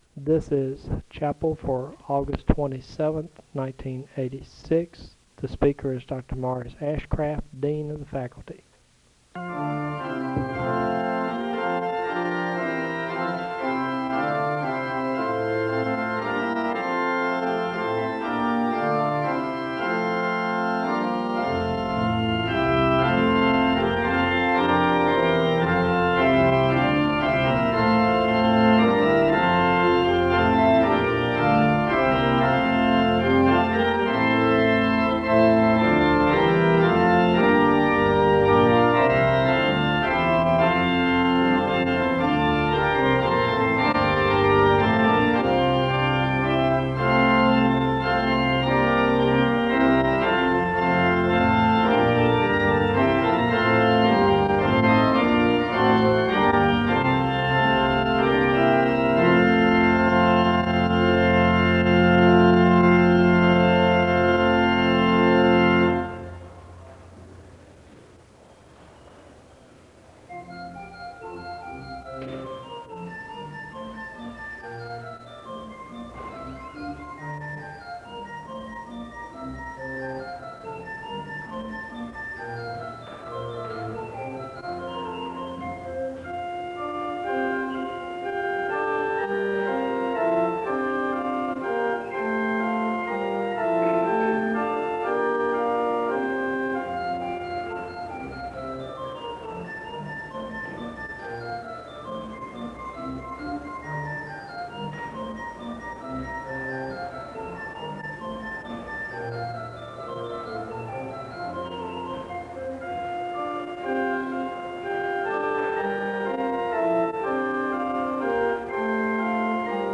The service begins with organ music (0:00-3:22). There is a Scripture reading and a prayer of thanksgiving (3:23-6:55). The choir sings a song of worship (6:56-11:32).